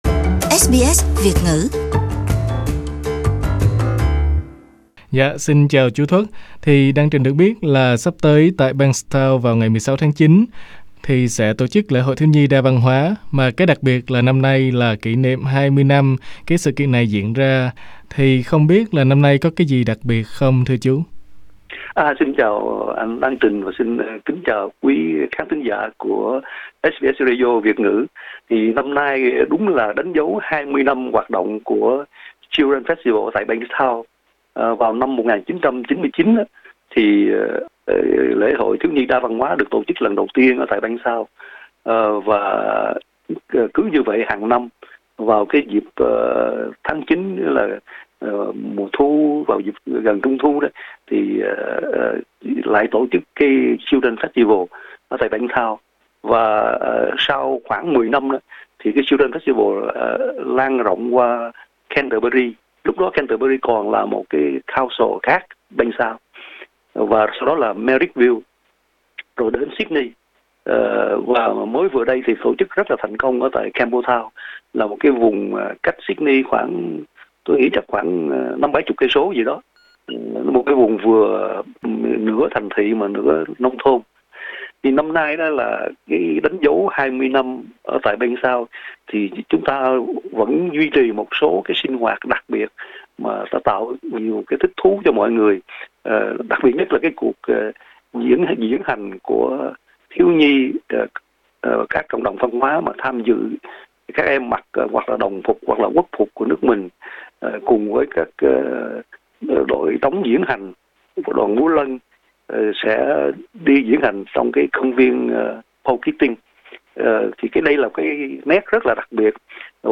SBS Vietnamese phỏng vấn